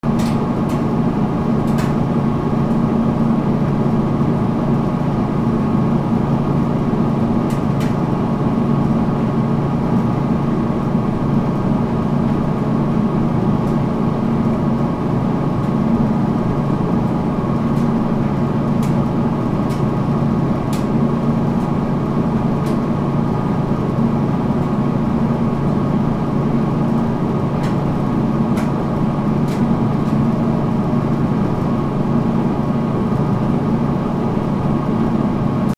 乾燥機
/ M｜他分類 / L10 ｜電化製品・機械
『ゴー』